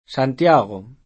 vai all'elenco alfabetico delle voci ingrandisci il carattere 100% rimpicciolisci il carattere stampa invia tramite posta elettronica codividi su Facebook Santiago [ S ant L#g o ; port. S9 nt L#G u ; sp. S ant L#G o ] top.